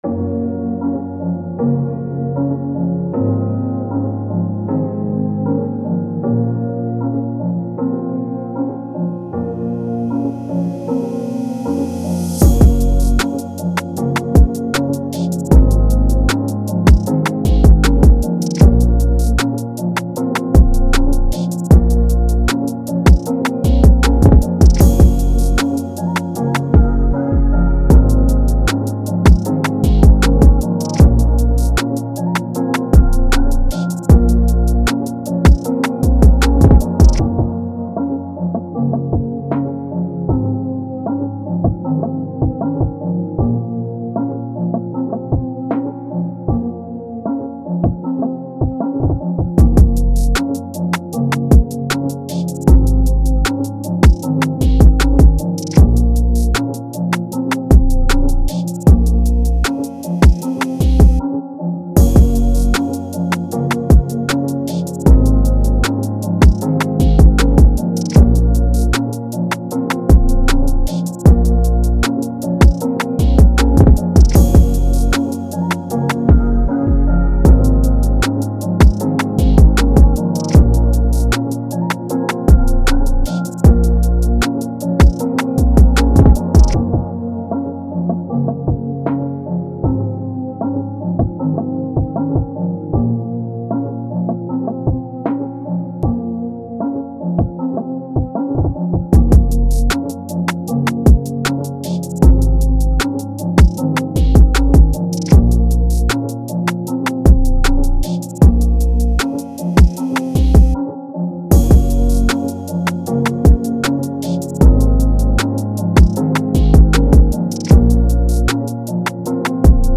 Pop, R&B
Bb Minor